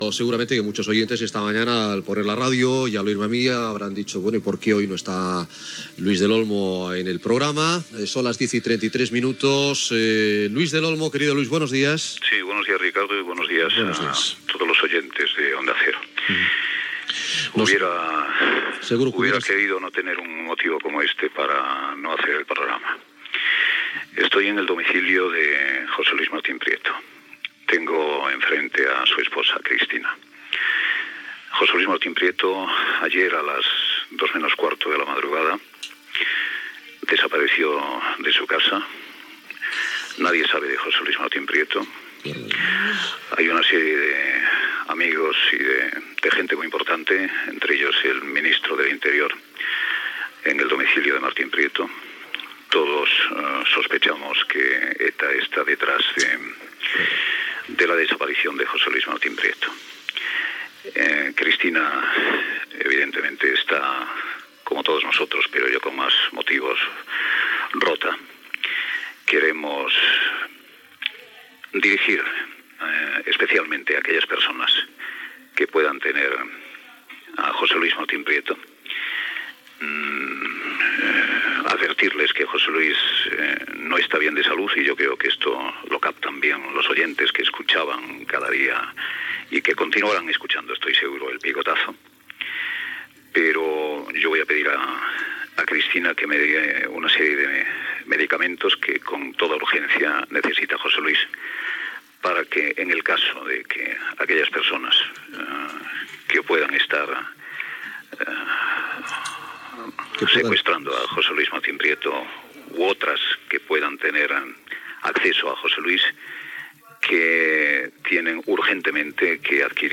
Luis del Olmo intervé des del domicili del periodista José Luis Martín Prieto, segrestat suposadament per ETA.
Info-entreteniment